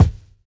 Kickdrum Sample F# Key 04.wav
Royality free kickdrum sound tuned to the F# note.
.WAV .MP3 .OGG 0:00 / 0:01 Type Wav Duration 0:01 Size 39,27 KB Samplerate 44100 Hz Bitdepth 16 Channels Mono Royality free kickdrum sound tuned to the F# note.
kickdrum-sample-f-sharp-key-04-adL.mp3